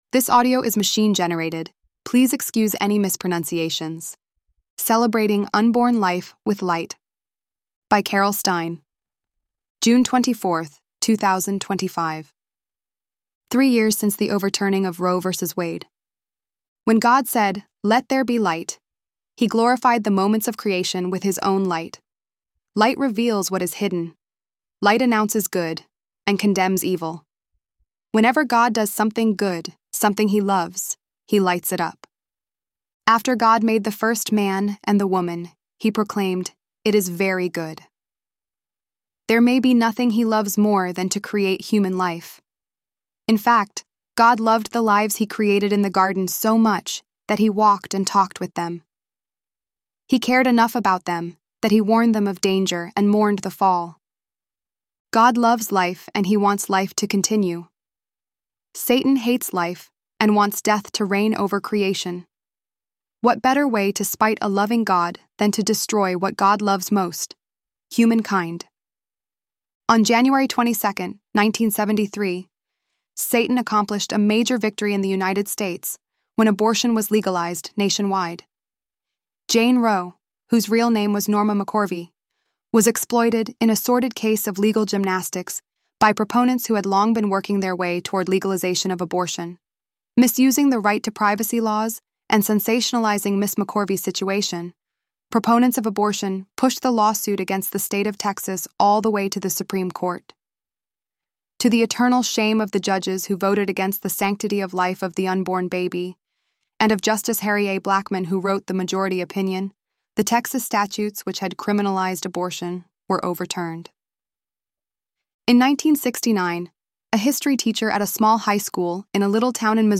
ElevenLabs_6.24_Light.mp3